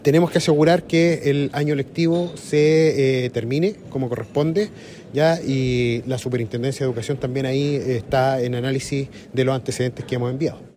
Por su parte, el seremi de Educación, Carlos Benedetti, informó que deben asegurar el cierre del año escolar y que la Superintendencia de Educación está analizando todos los antecedentes que se han enviado.